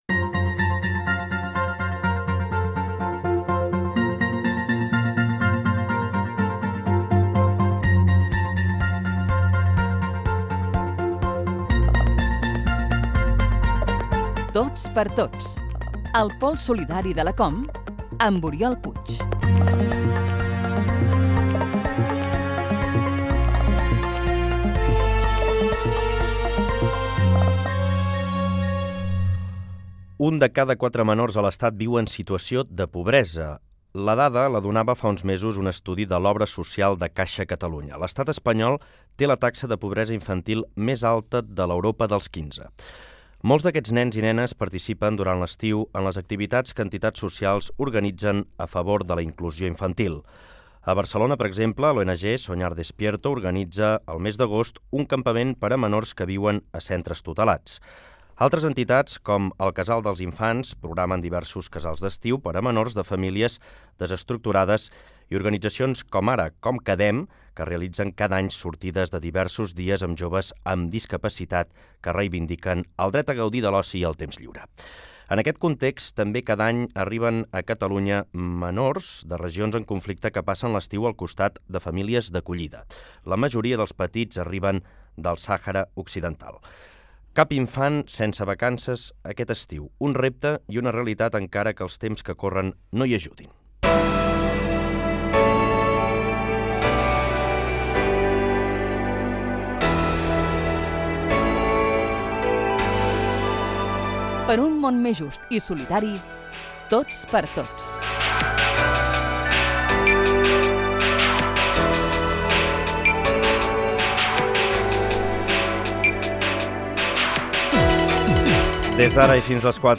Fragment extret de l'arxiu sonor de COM Ràdio